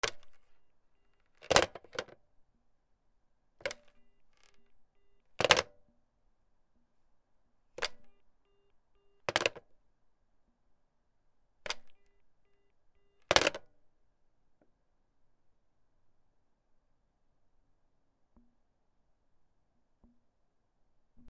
/ G｜音を出すもの / G-01 機器_電話
電話 受話器の上げ下げ(強め)